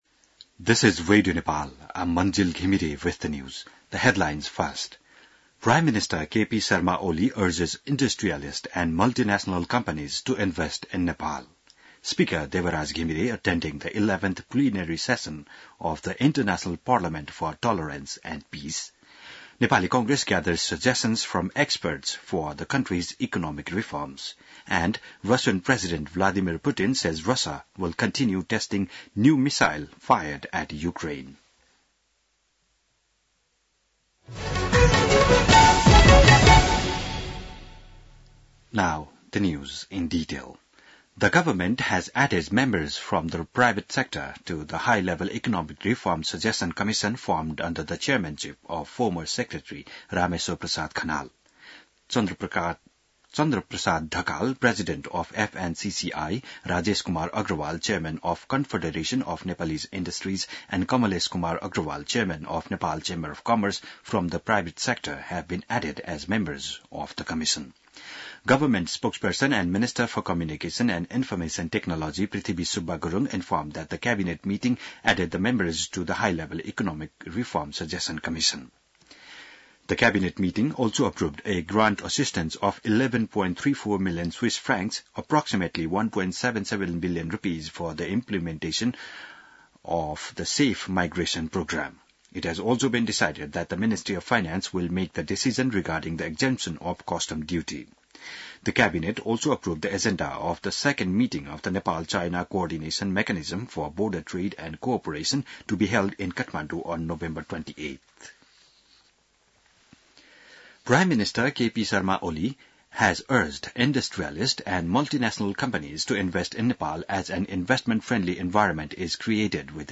बिहान ८ बजेको अङ्ग्रेजी समाचार : ९ मंसिर , २०८१